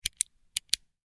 دانلود صدای فشار دادن سر خودکار از ساعد نیوز با لینک مستقیم و کیفیت بالا
جلوه های صوتی